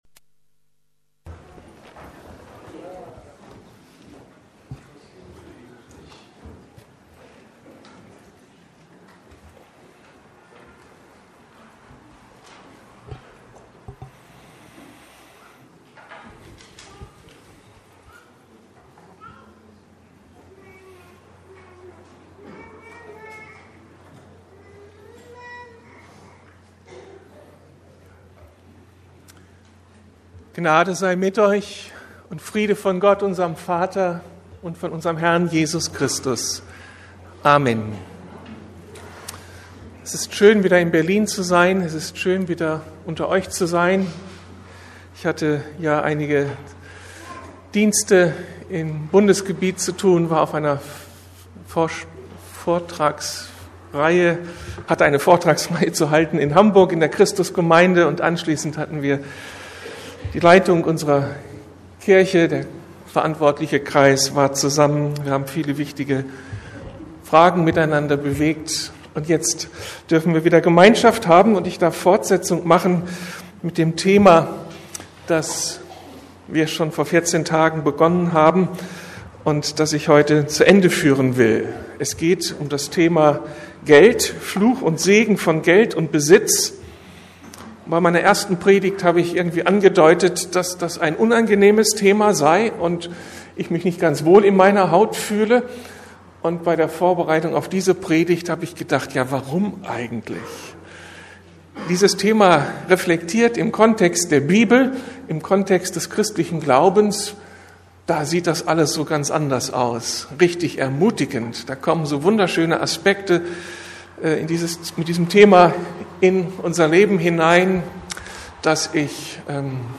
Fluch und Segen von Geld und Besitz, Teil2 ~ Predigten der LUKAS GEMEINDE Podcast